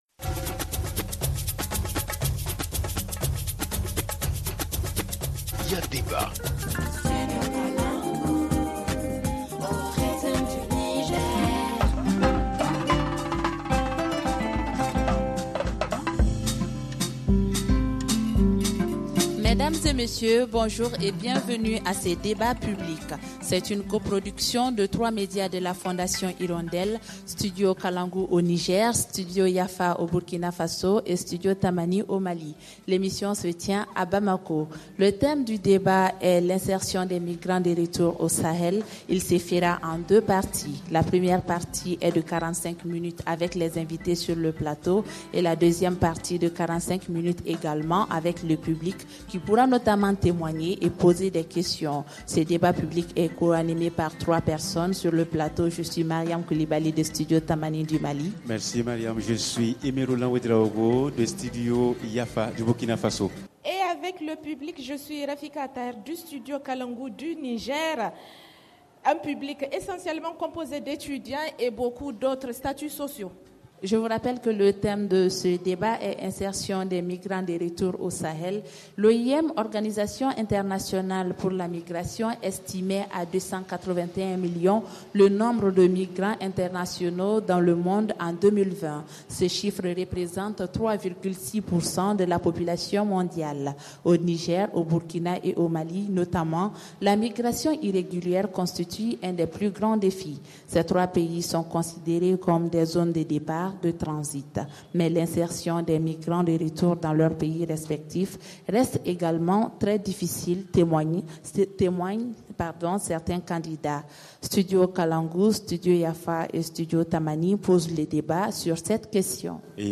Studio Kalangou, Studio Yafa et Studio Tamani posent le débat avec nos invités.